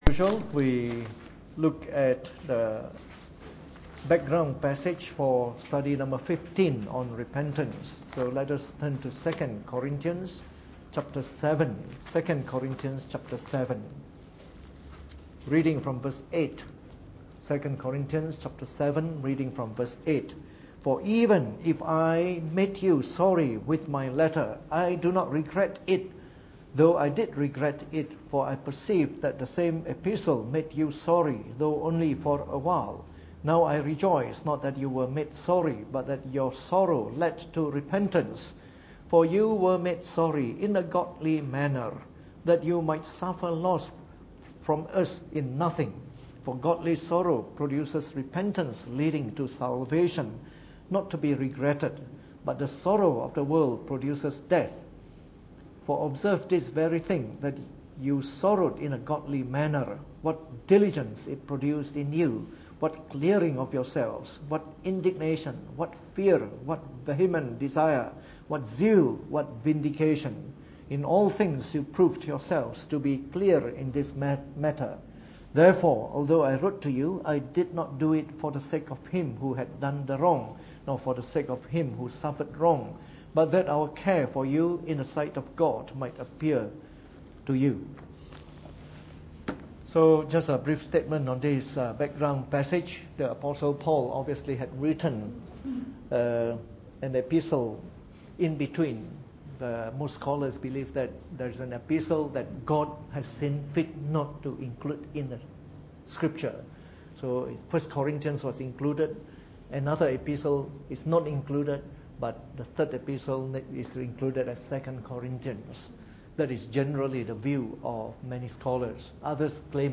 Preached on the 18th of May 2016 during the Bible Study, from our series on the Fundamentals of the Faith (following the 1689 Confession of Faith).